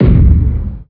deepthud.wav